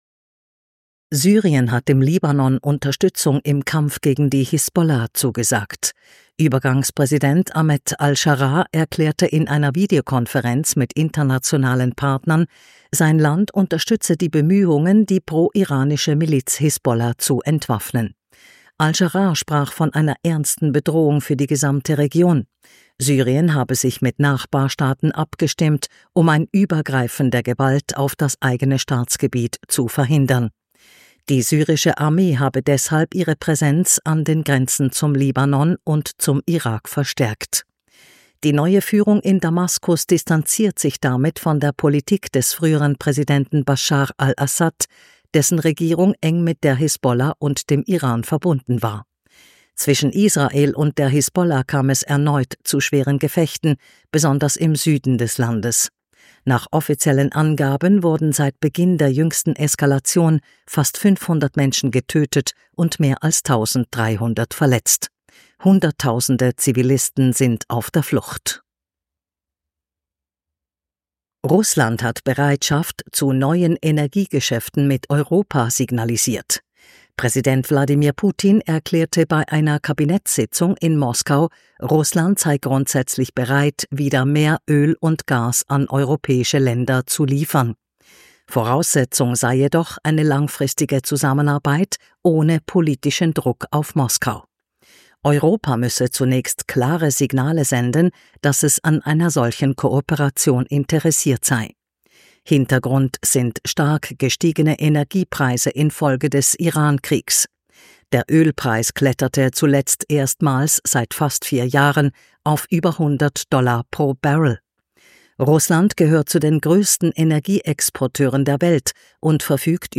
Kontrafunk aktuell 10.3.2026 – Nachrichten vom  10.3.2026